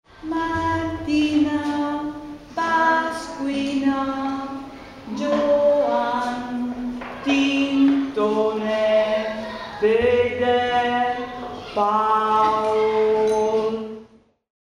La melodia era basata sulla classica cadenza melodica data dal suono a scala per cinque campane secondo il sistema di suono bresciano: